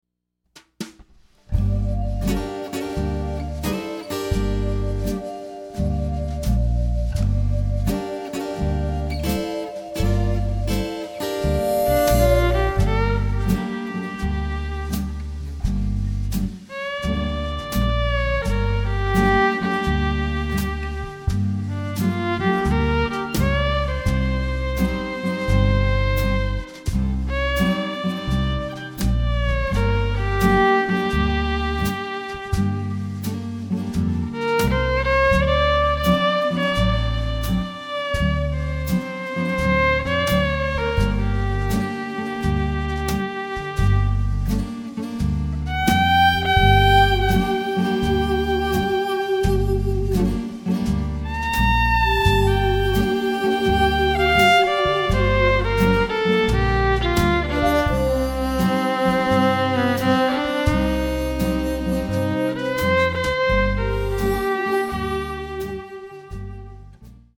The sound of the disc is warm and acoustic